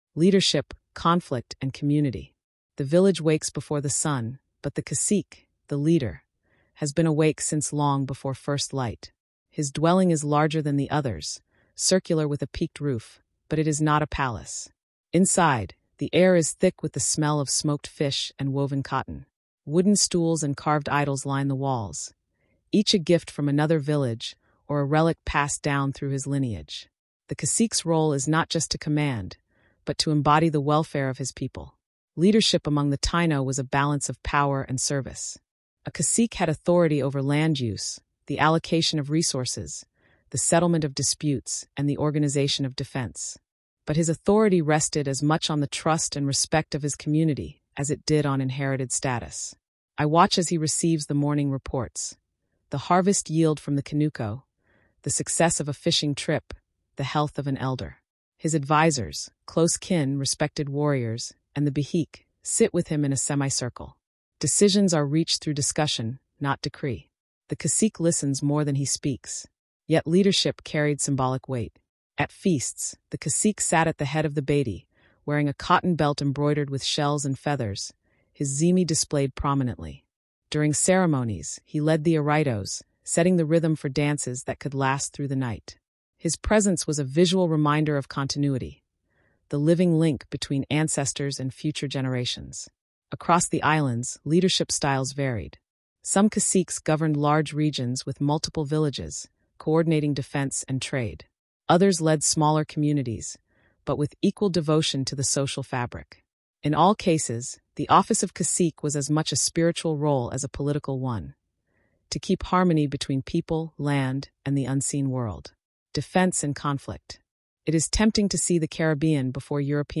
Discover the role of the cacique, the strategies and rituals of conflict, and the social bonds that kept villages thriving across Hispaniola, Cuba, Puerto Rico, and Jamaica. Told in vivid Smithsonian-style storytelling, this episode reveals the human strength and cultural unity of the Caribbean’s first great civilization.